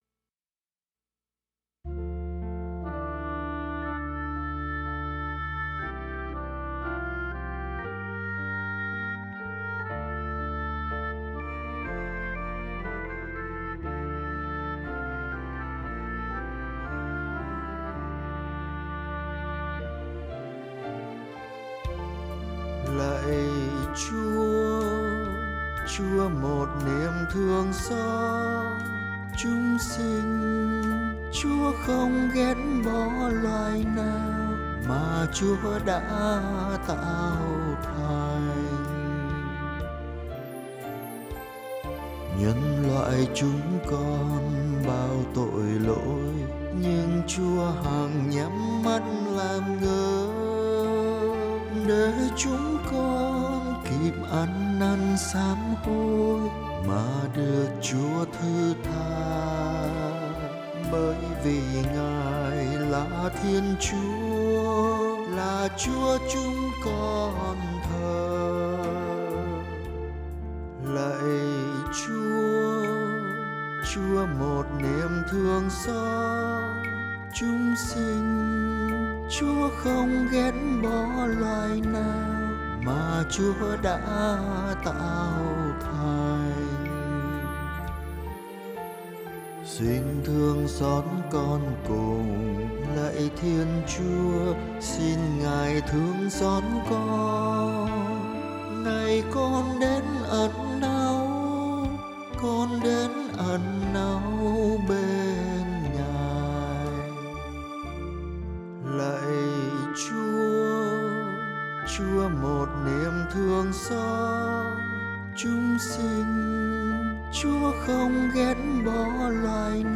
ChuaNiemThuongXot_Sop.mp3